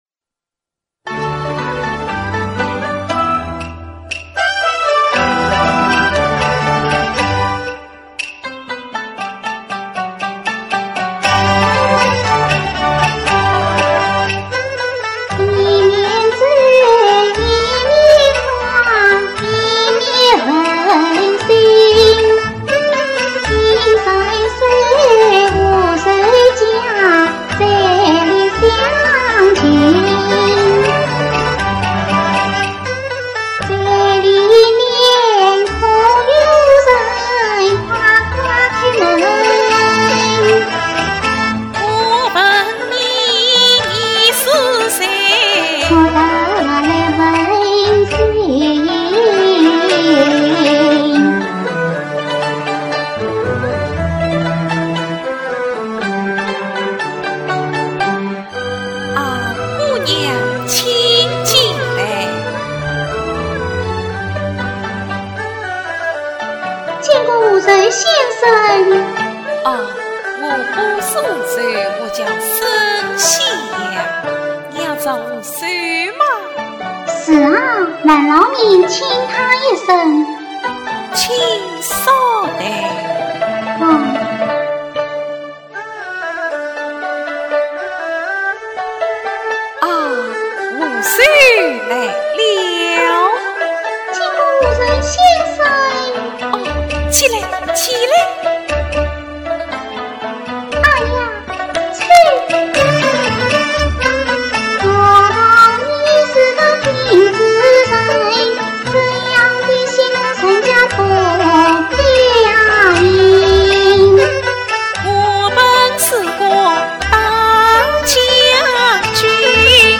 越剧《山河恋-送信》
唱腔流畅